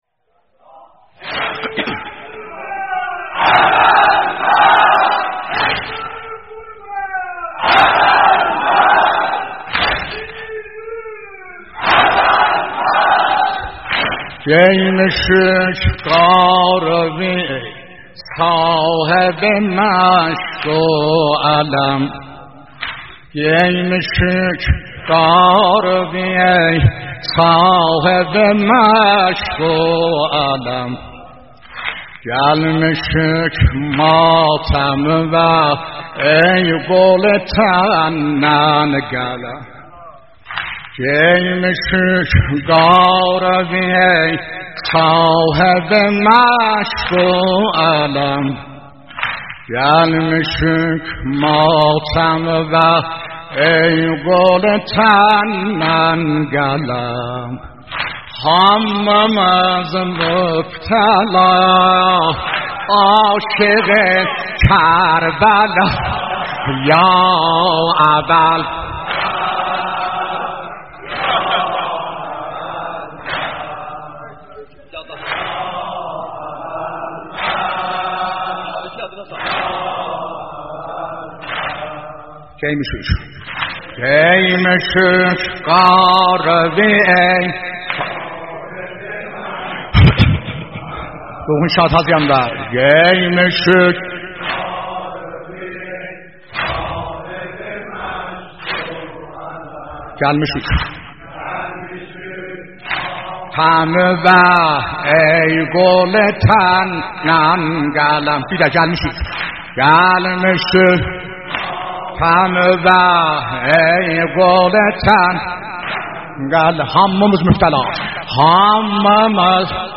┄━═✿♡﷽♡✿═━┄ ➖➖➖➖➖ گیموشوک قاره وی ای صاحب مشگ و علم ➖➖➖➖➖ توسل به حضرت ابوالفضل (ع) .. .. .. با صدای مرحوم استاد